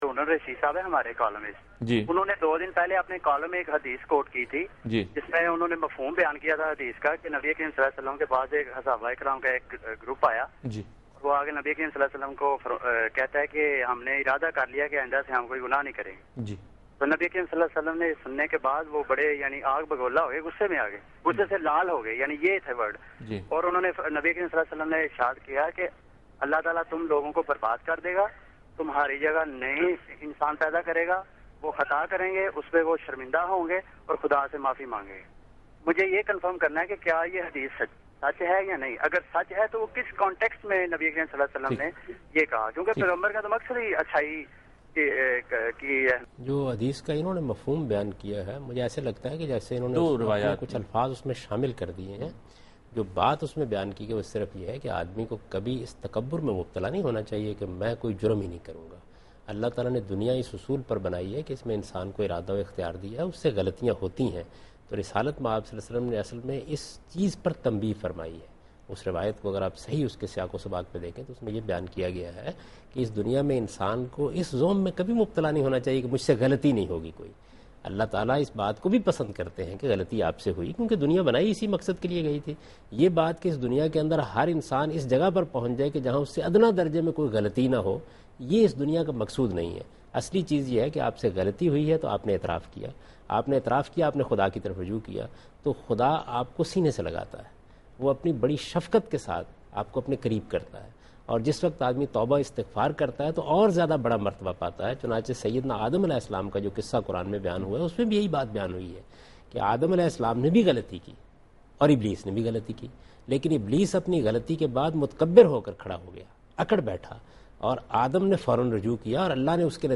Answer to a Question by Javed Ahmad Ghamidi during a talk show "Deen o Danish" on Dunya News TV